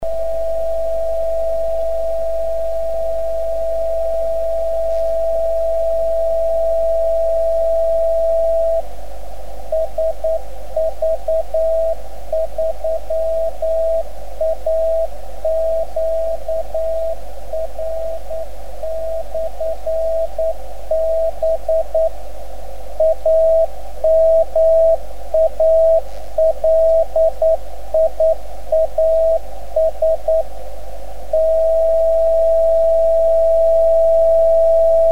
10m Baken
Die hier aufgeführten Stationen wurden selbst empfangen.